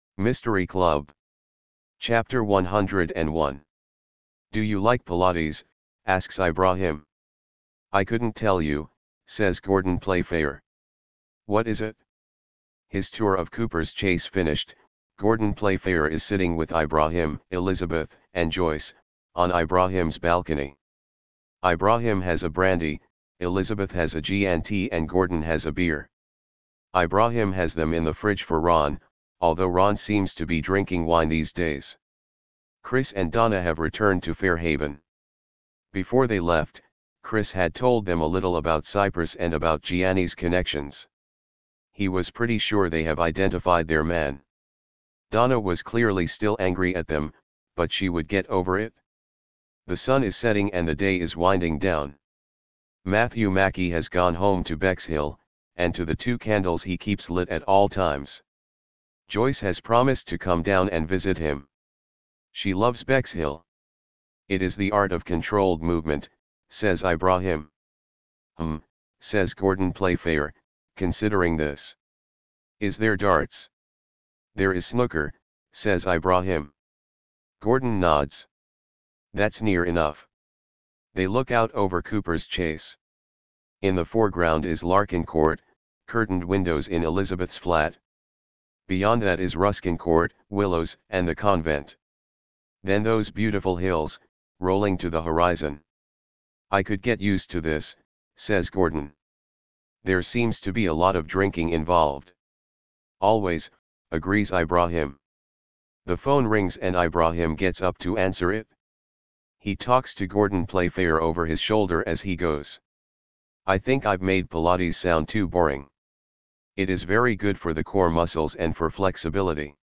The Thursday Murder Club Audiobook and PDF version Chapter 101 to 115